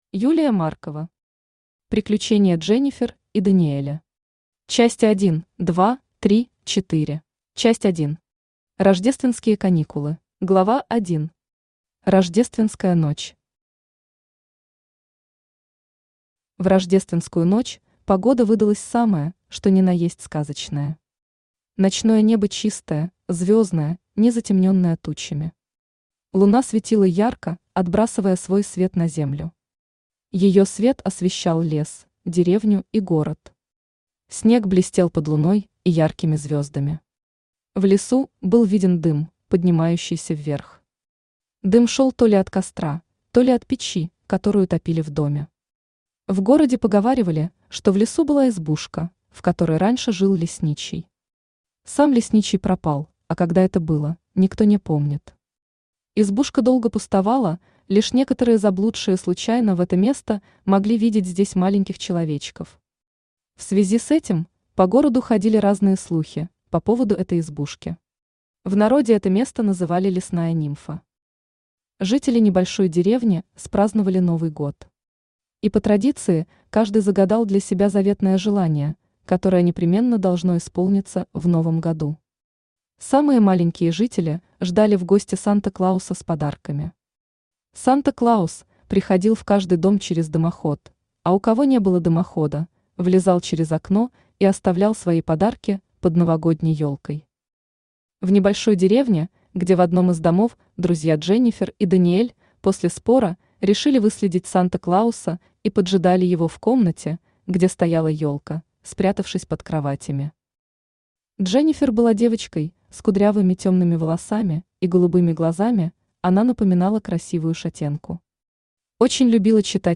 Части 1, 2, 3, 4 Автор Юлия Борисовна Маркова Читает аудиокнигу Авточтец ЛитРес.